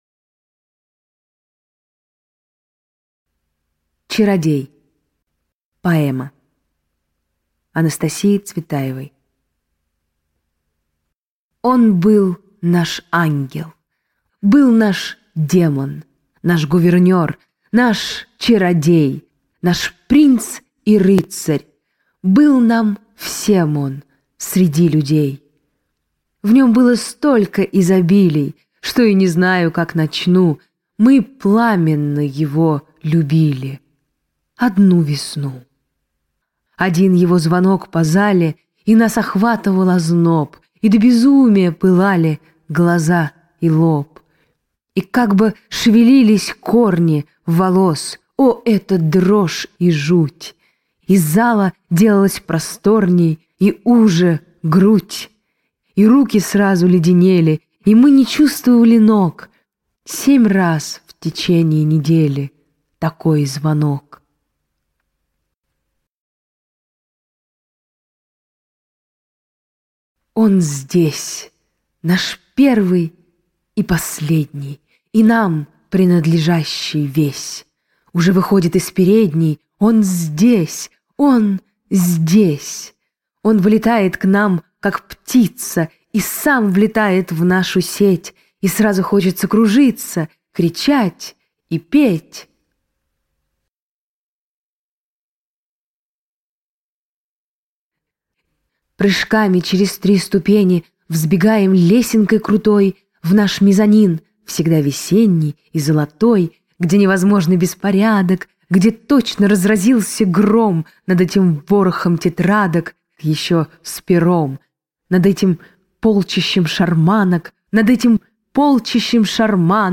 Аудиокнига Поэмы. Читает Анна Большова | Библиотека аудиокниг